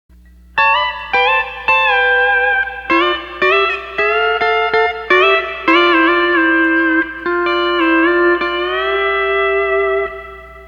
Steel Guitar Tab Page 11 (E9th 3RD Pedal)
Tab523 - Mmaximum BC Pedal Action Tab